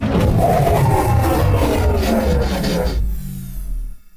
combat / creatures / cylarkan / she / die1.ogg
die1.ogg